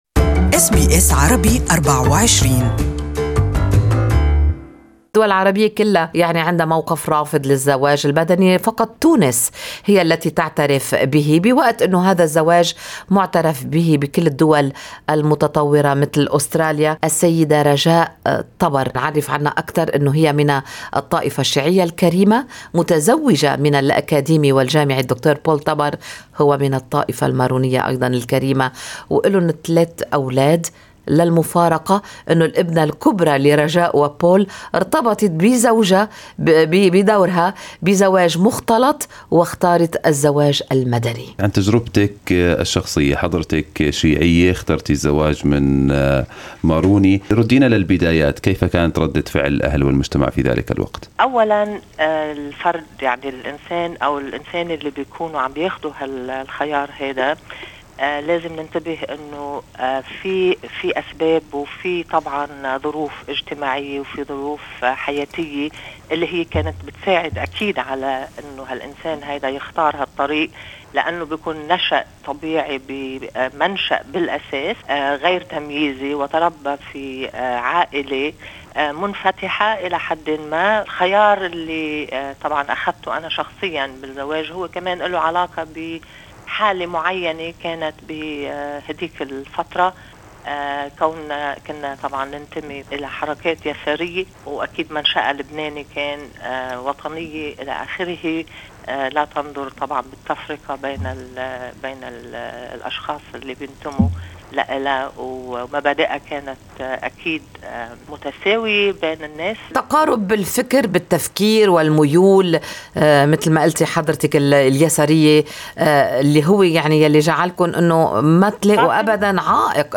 أسترالية لبنانية من الطائفة الشيعية تتحدث عن تجربة زواجها المدني من مسيحي ماروني.